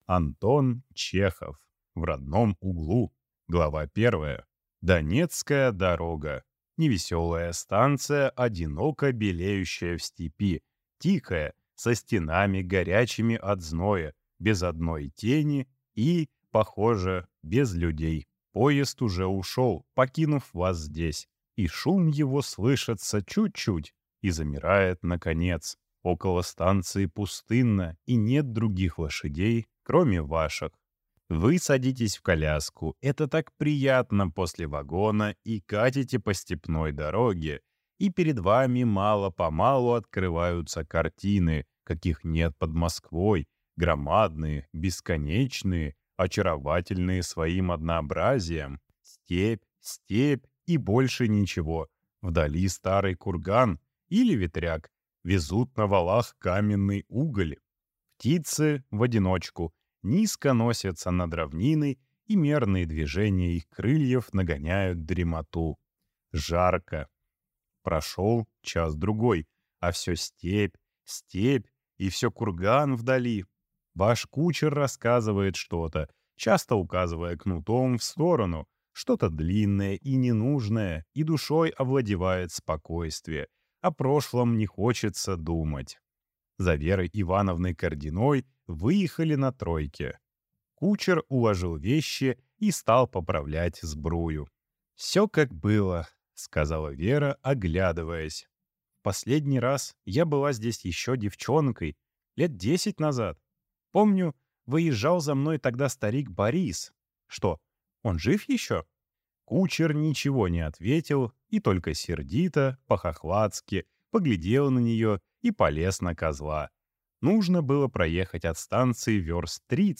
Аудиокнига В родном углу | Библиотека аудиокниг